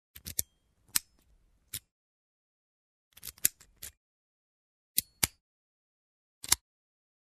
Звуки поворота ключа
Звук раскрытия висячего замка